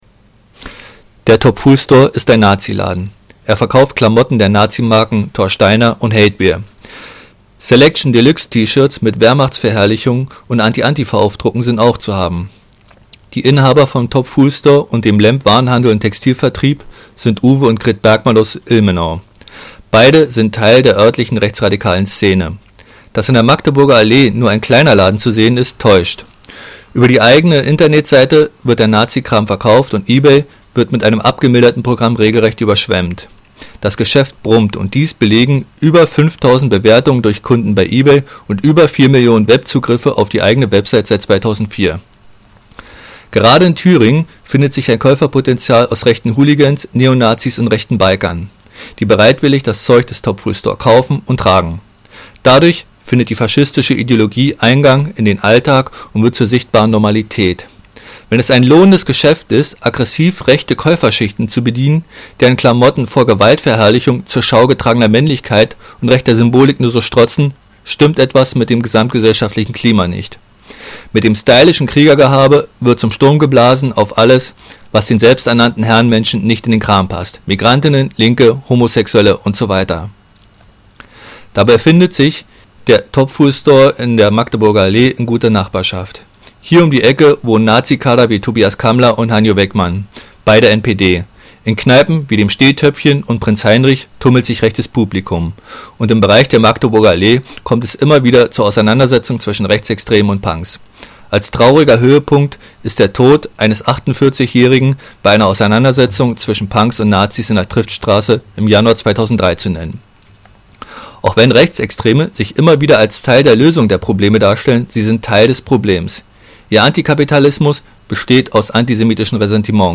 Hier ein vorproduzierter Redebeitrag zum Naziladen "top fuel store":
Naziladen-Rede.mp3